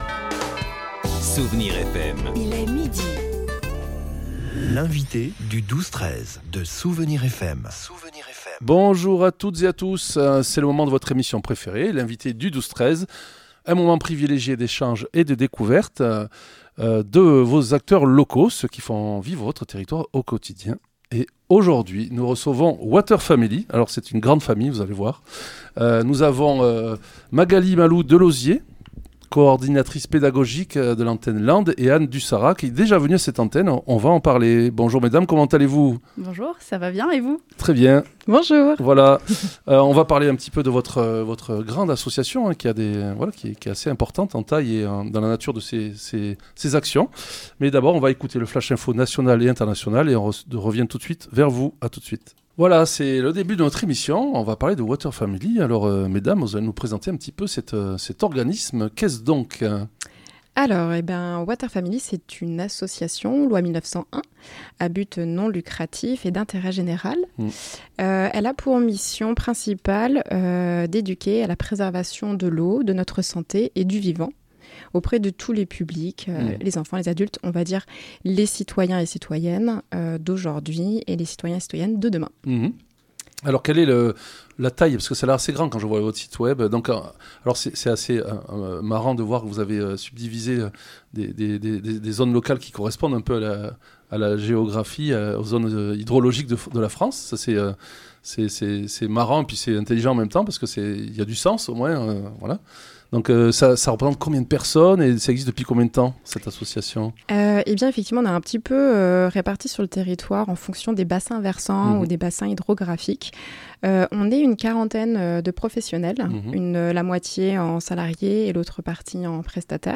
L'invité(e) du 12-13 de Soustons recevait aujourd'hui Water Family